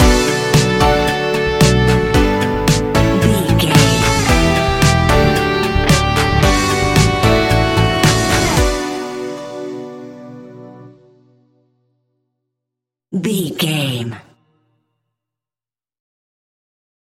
Ionian/Major
B♭
ambient
electronic
new age
chill out
downtempo
synth
pads